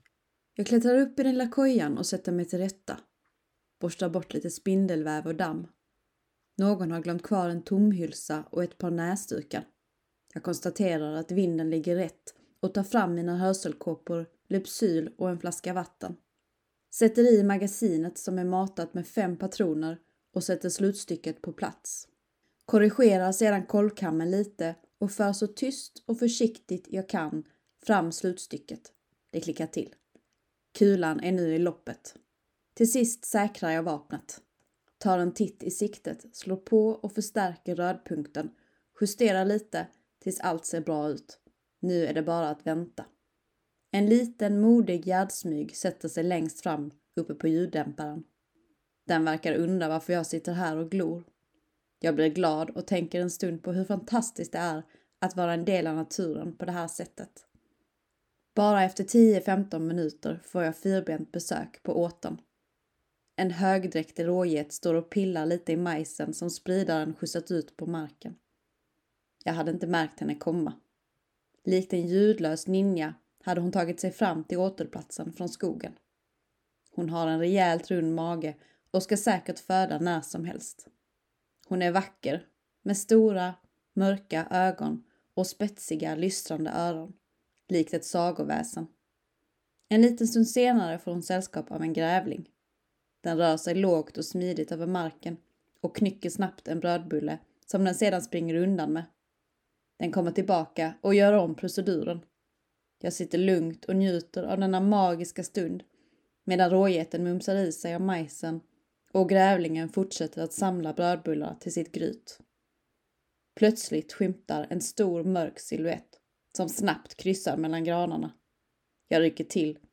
Ljudnovell
Vi fick använda rösten som arbetsverktyg i samband med en ljudinspelning av ett utdrag ur novellen.